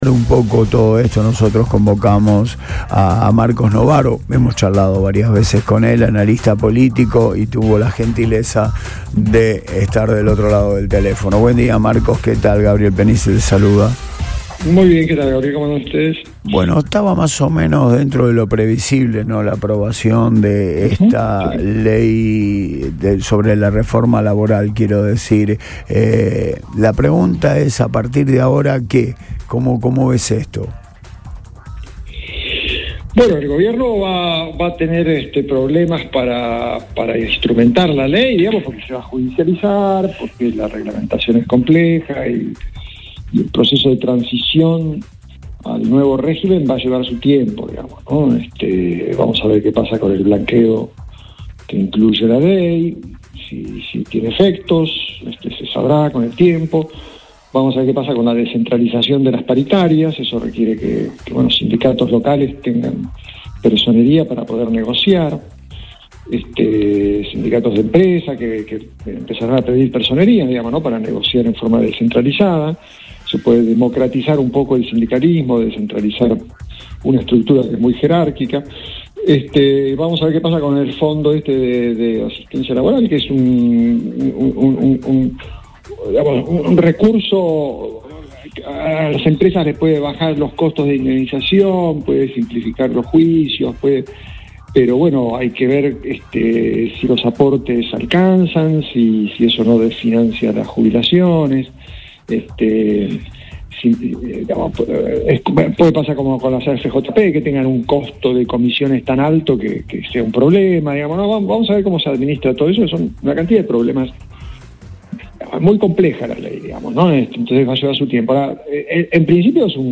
El analista político Marcos Novaro dialogó con el equipo de Radio Boing y se refirió a la reciente aprobación de la reforma laboral, a la coyuntura política y a los desafíos económicos que enfrenta el Gobierno nacional.